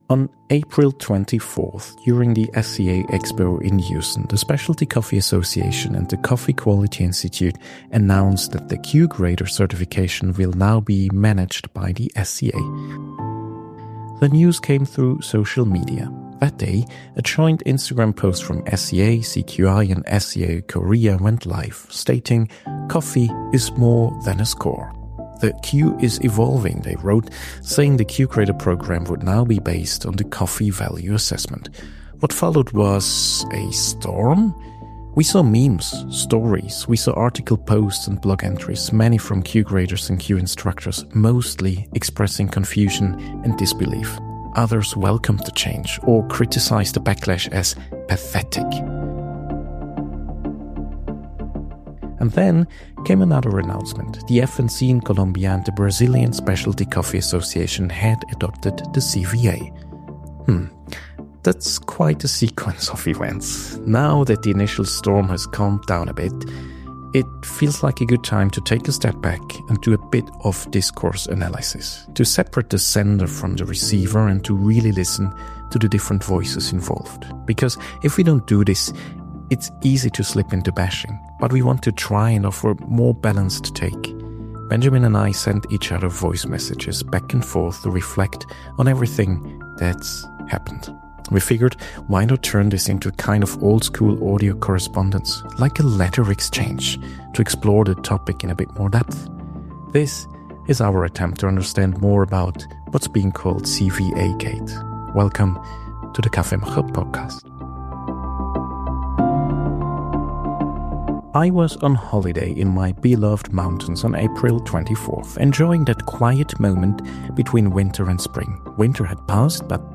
| Streitgespräch mit Jan van Aken (Die Linke) 47:31 Play Pause 9h ago 47:31 Play Pause Später Spielen Später Spielen Listen Gefällt mir Geliked 47:31 Wie wird die Bundeswehr "kriegstüchtig”? Und ist das überhaupt nötig?